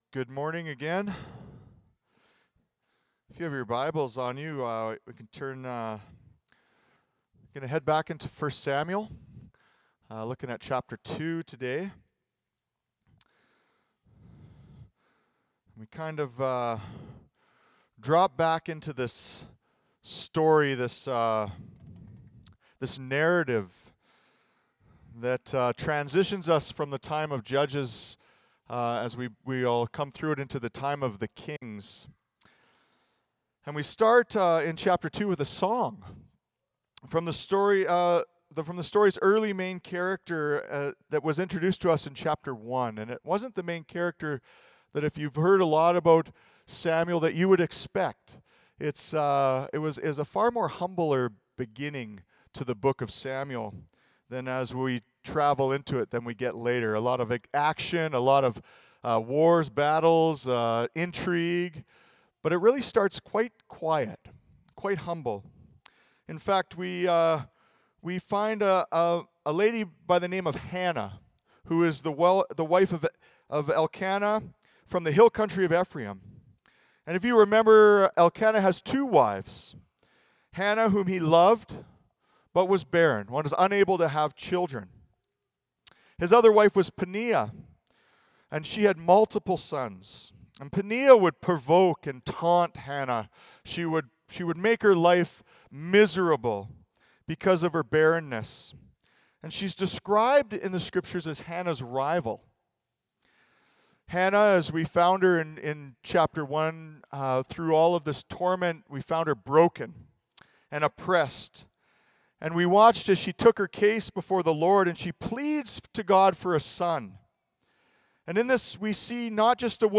Audio Sermon Library One Song Two Sons.